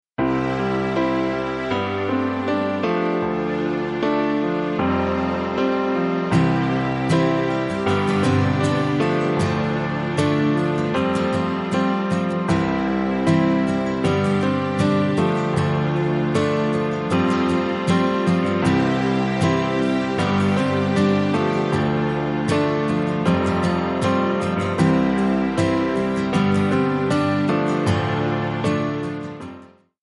MPEG 1 Layer 3 (Stereo)
Backing track Karaoke
Pop, 1990s